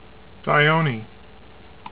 "dy OH nee" ) is the twelfth of Saturn's known satellites: